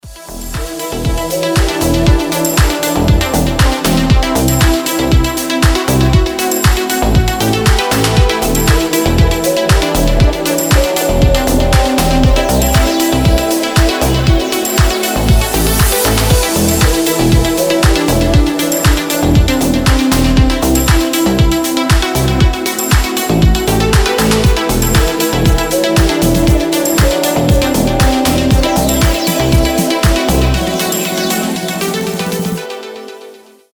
• Качество: 320, Stereo
deep house
Indie Dance